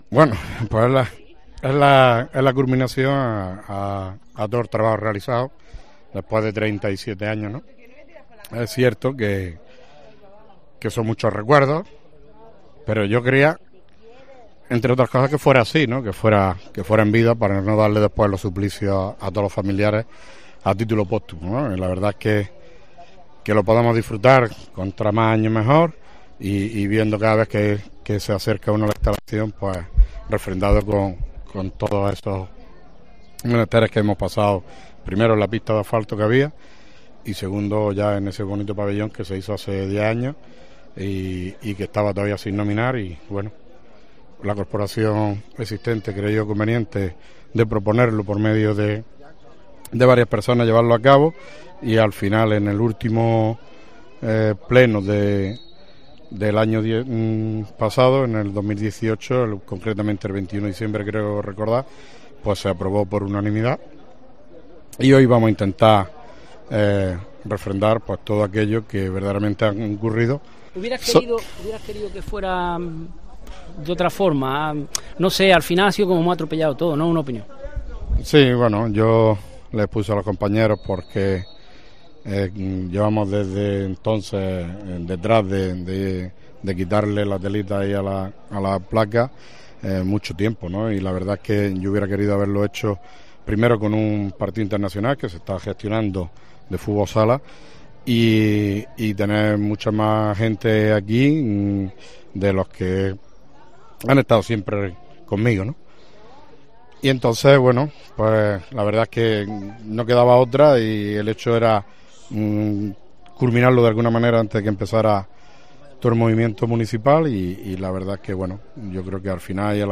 Acto homenaje